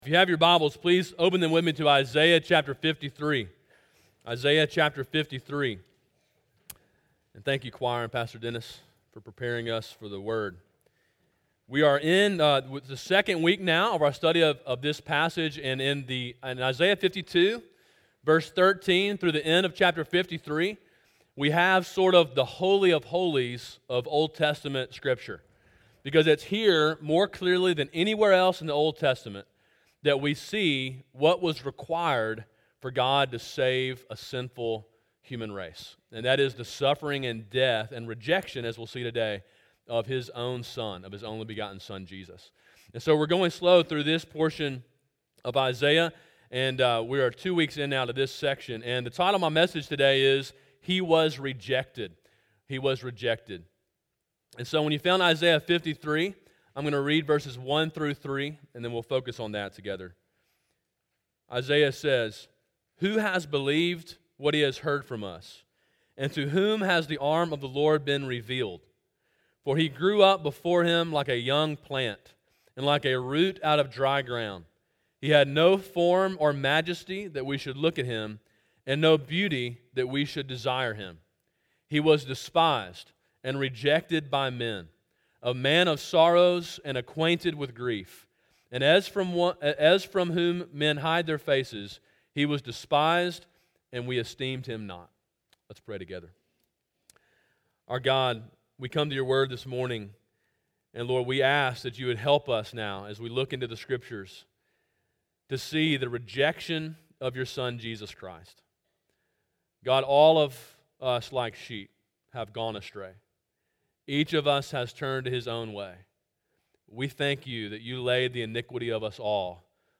Sermon: “He Was Rejected” (Isaiah 53:1-3)
Sermon in a series on the book of Isaiah.